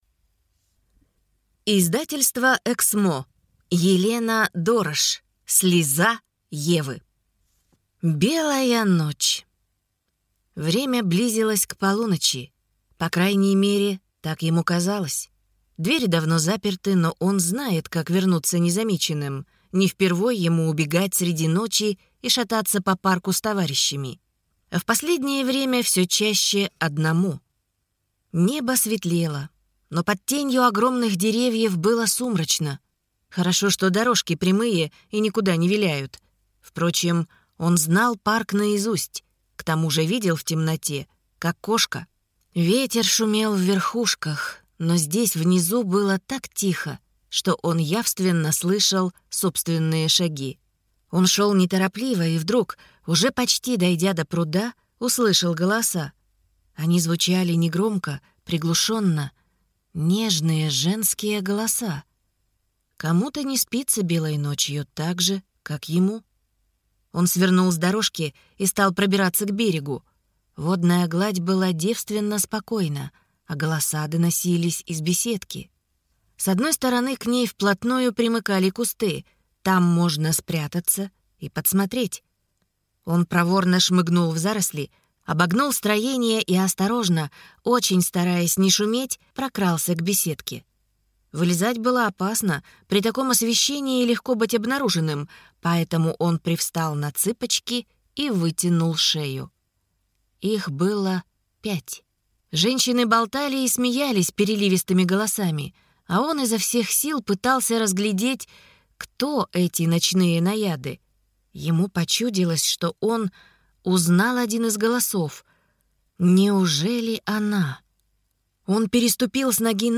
Аудиокнига Слеза Евы | Библиотека аудиокниг
Прослушать и бесплатно скачать фрагмент аудиокниги